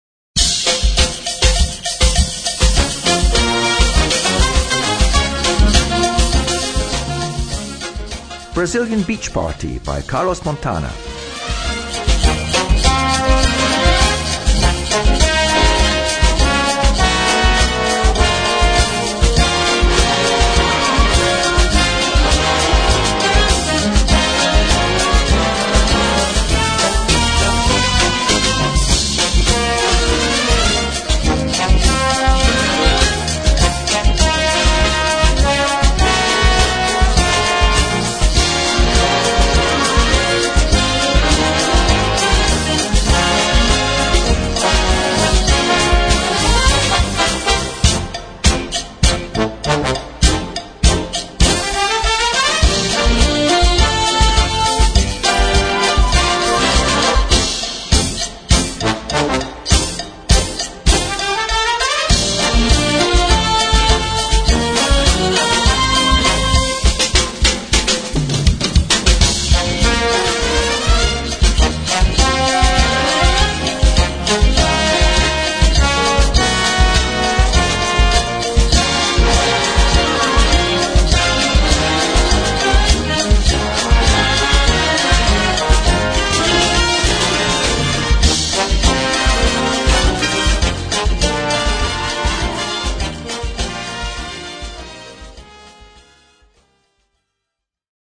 Fiesta Tropical & Latino
Noten für Blasorchester, oder Big Band, oder Brass Band.